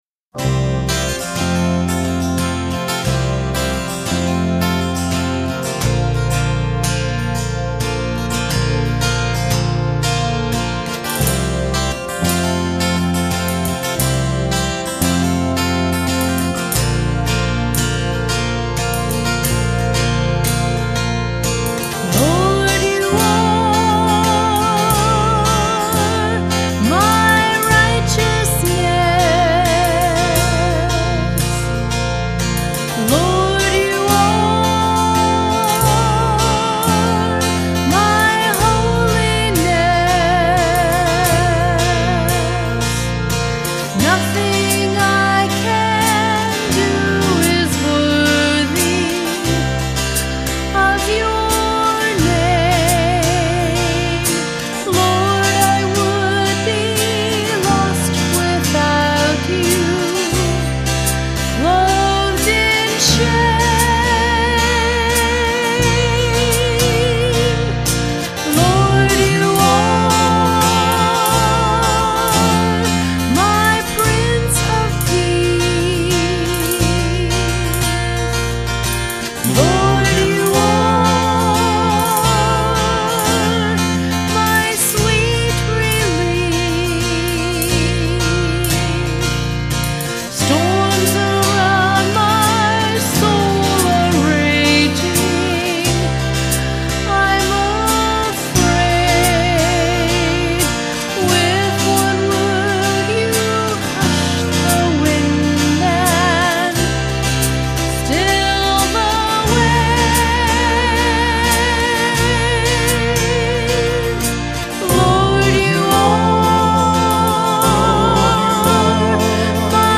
This recording is similar to the version we do in concert.